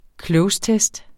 Udtale [ ˈklɔws- ]